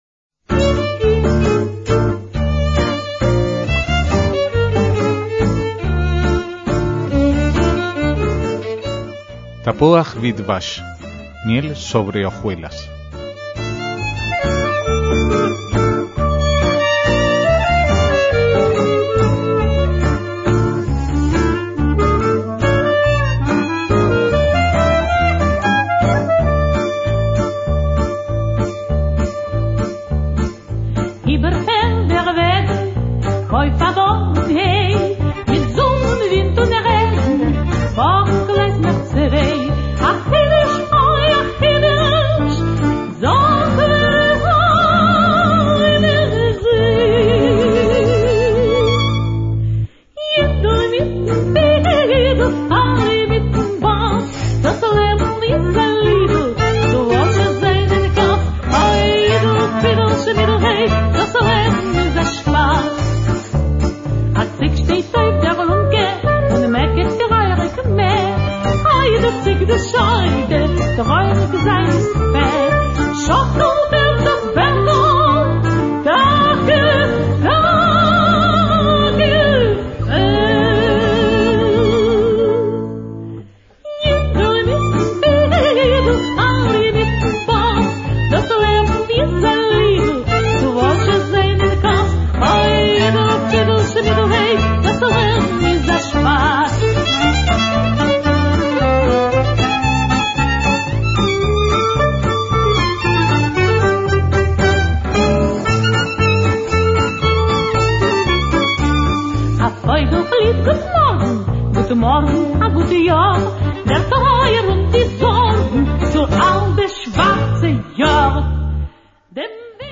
MÚSICA ÍDISH